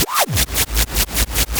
RI_RhythNoise_150-02.wav